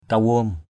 /ka-wo:m/ 1.
kawom.mp3